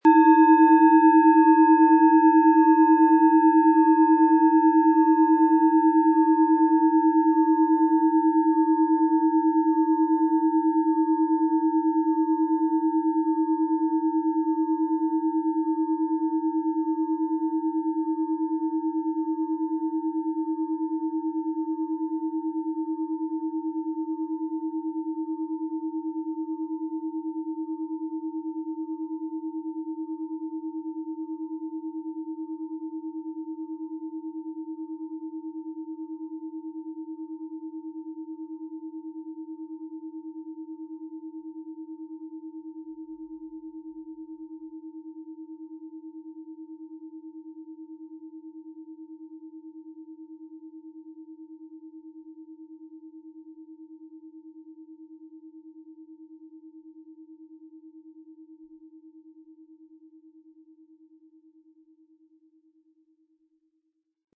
Der Klöppel lässt die Klangschale voll und harmonisch tönen.
PlanetentonWasserstoffgamma
SchalenformOrissa
MaterialBronze